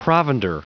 Prononciation du mot provender en anglais (fichier audio)
Prononciation du mot : provender